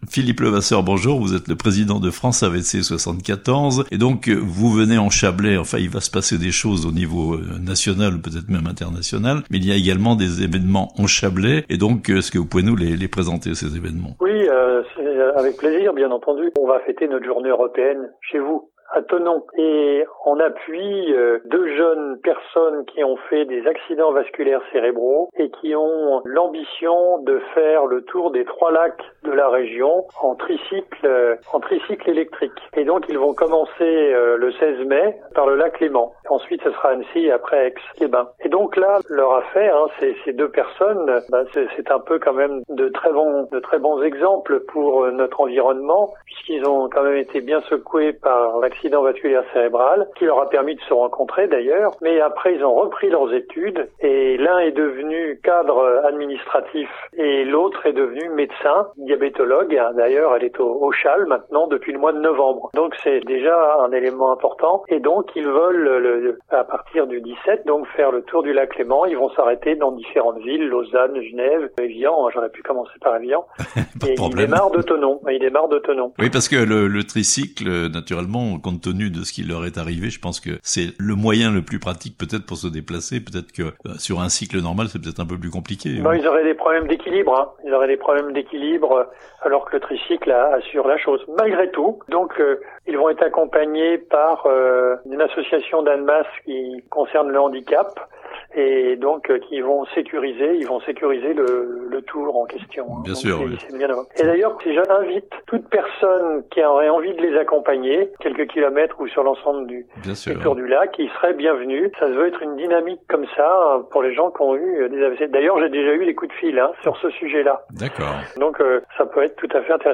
Une conférence et des rencontres à Thonon pour s'informer sur les risques d'AVC (interview)